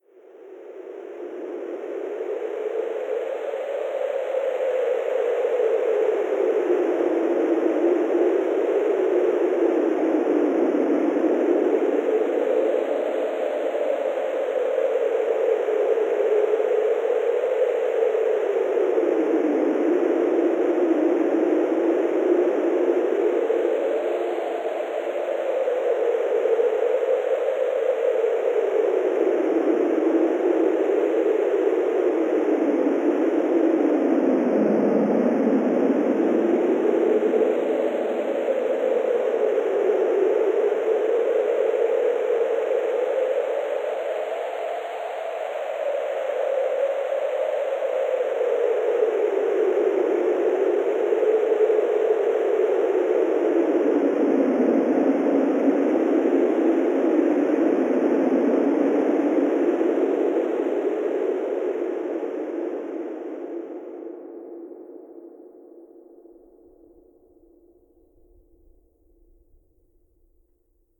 Wind, Synthesized, A